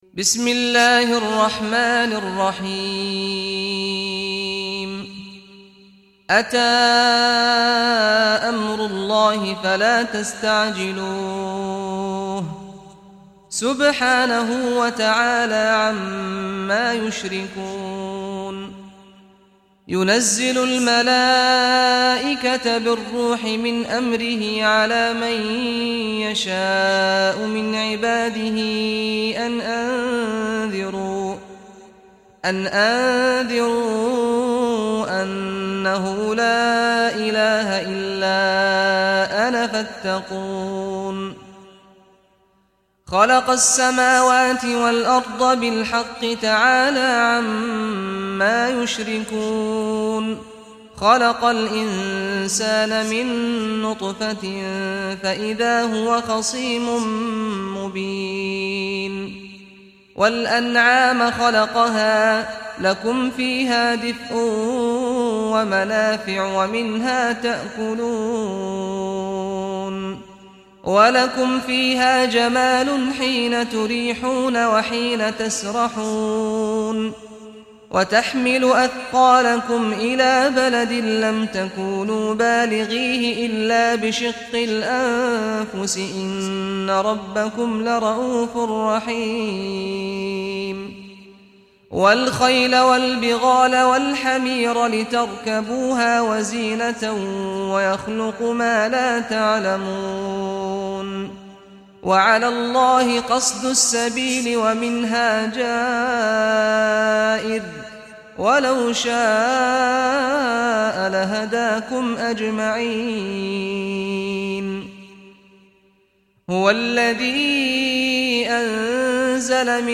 Surah An-Nahl Recitation by Sheikh Saad Ghamdi
Surah An-Nahl, listen or play online mp3 tilawat / recitation in Arabic in the voice of Imam Sheikh Saad al Ghamdi.